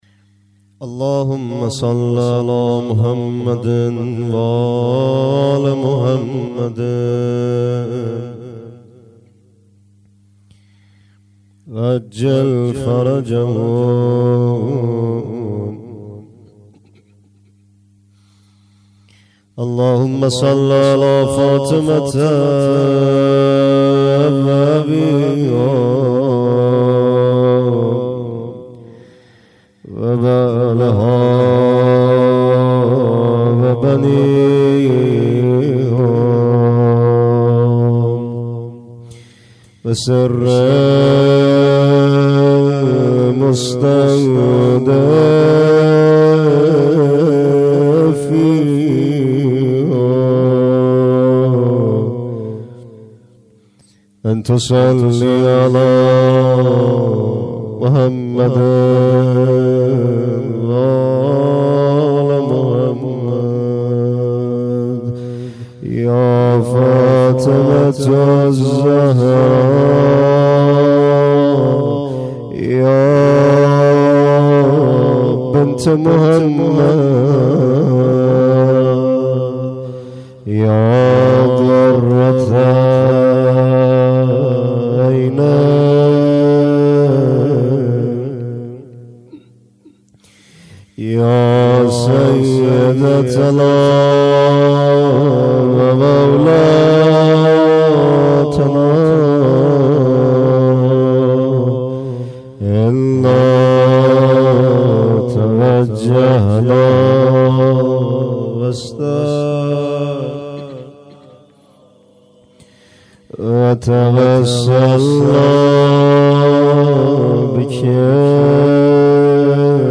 مناجات.